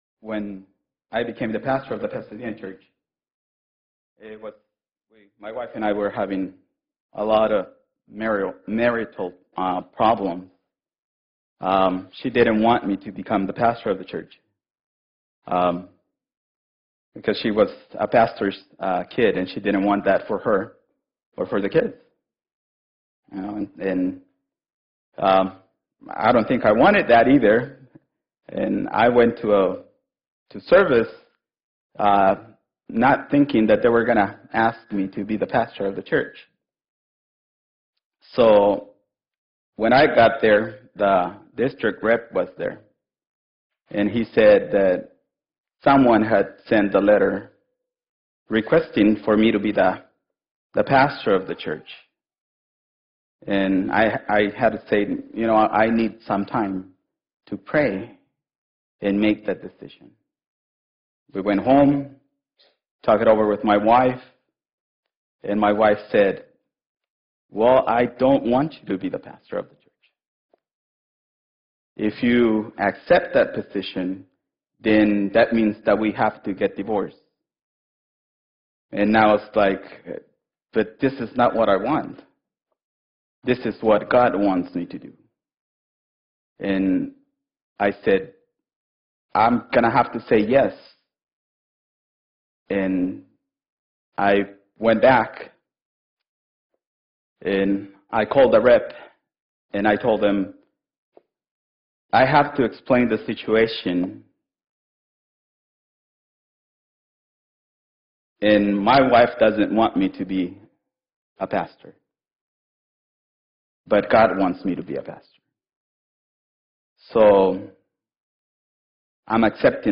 2-7-2015 sermon